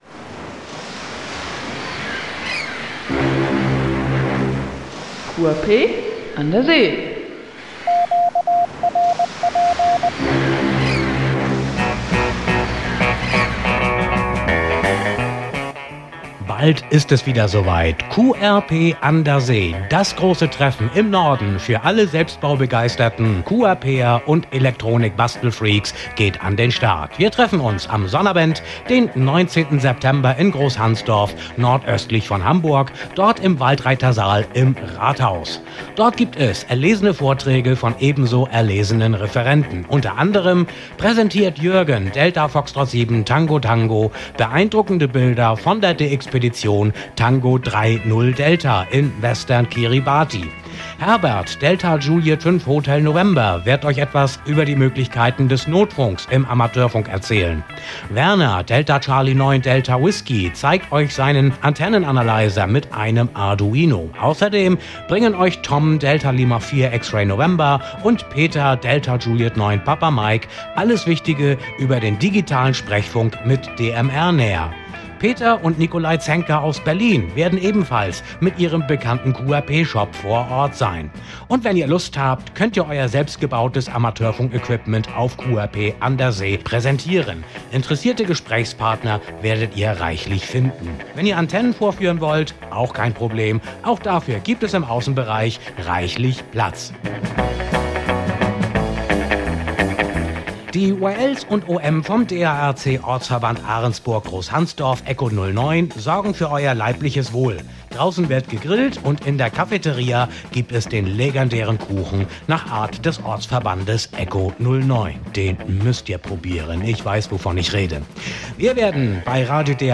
Info-Spot für die RADIO-DARC-Ausgabe von diesem Sonntag produziert.